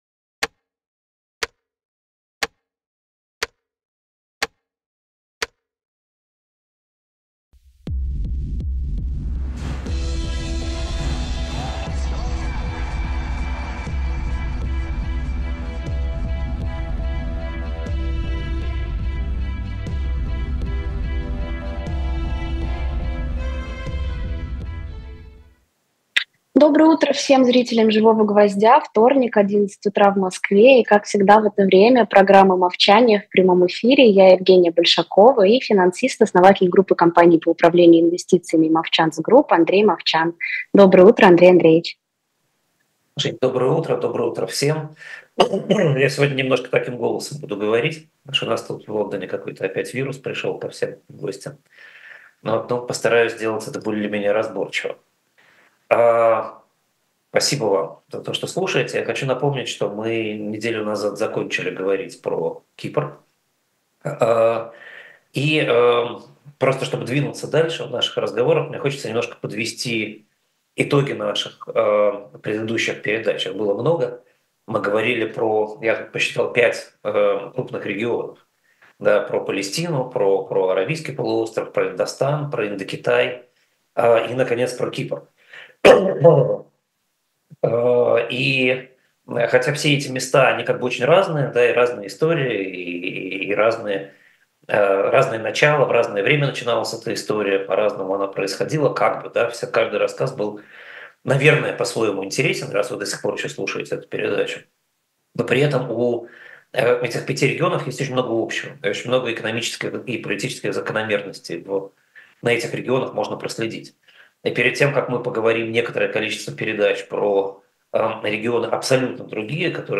Андрей Мовчан экономист